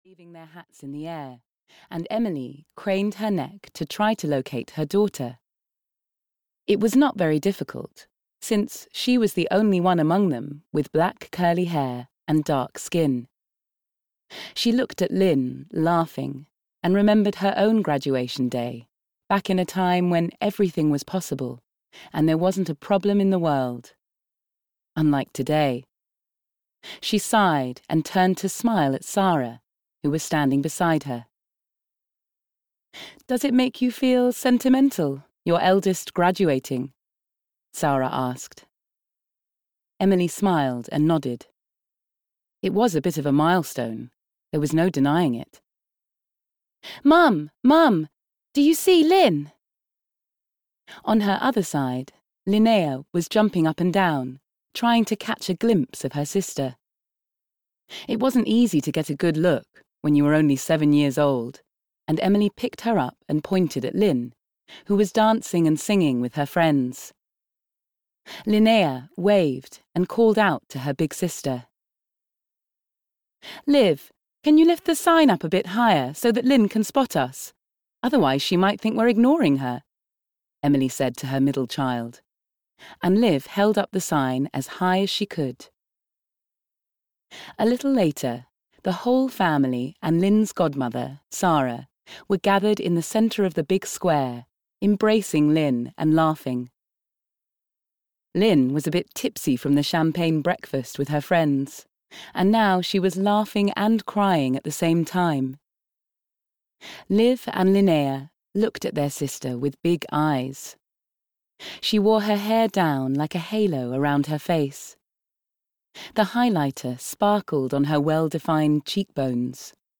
Finding Home on the Little Swedish Island (EN) audiokniha
Ukázka z knihy